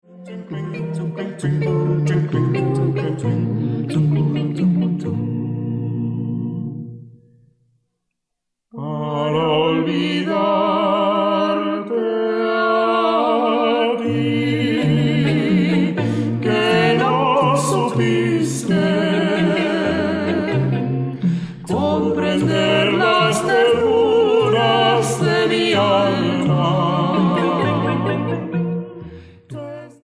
Sexteto Vocal